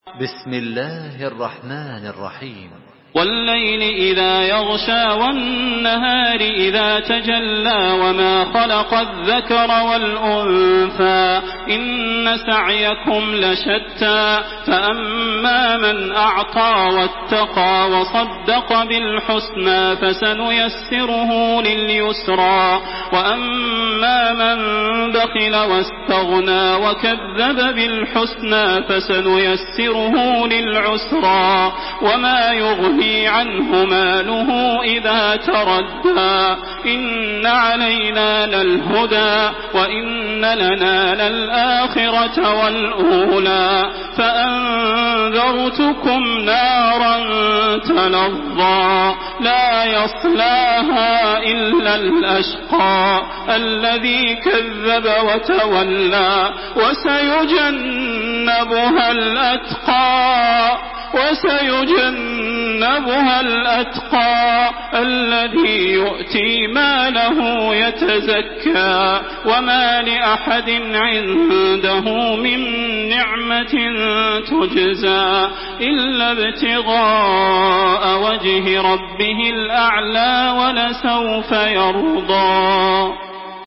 Surah Al-Layl MP3 by Makkah Taraweeh 1427 in Hafs An Asim narration.
Murattal Hafs An Asim